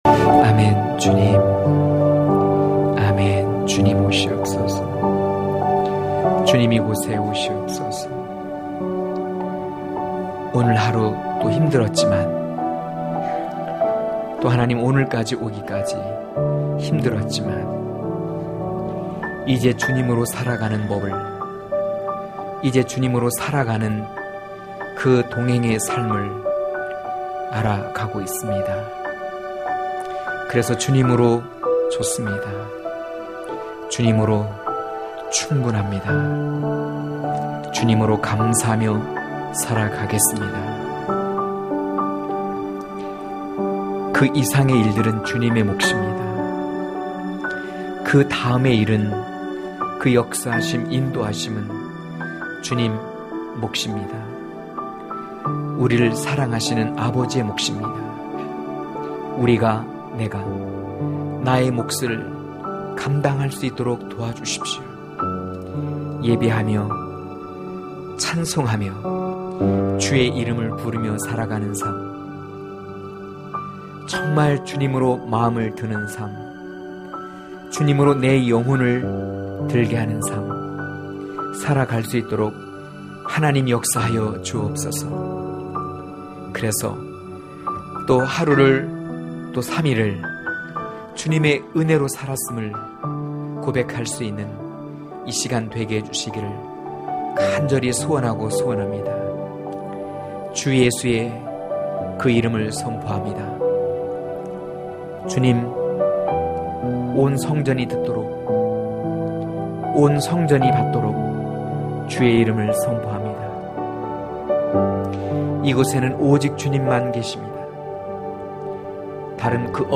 강해설교 - 03.솔로몬을 살린 술람미 여인(아1장9-17절)